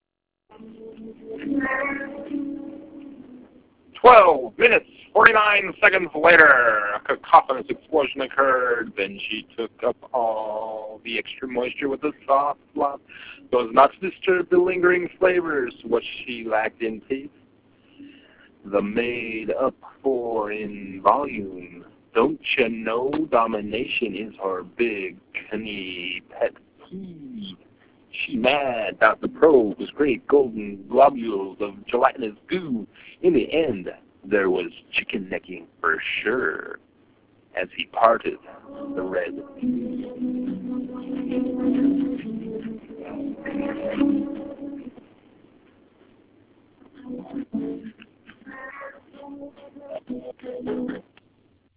Creator's Tags: gridpoem reading